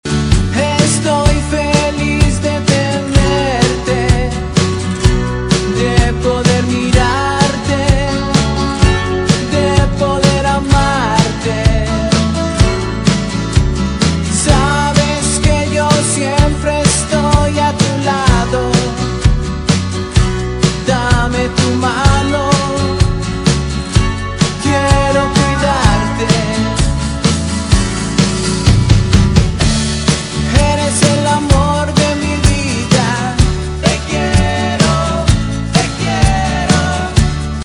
Músico, cantautor y multi-instrumentista
Rock latino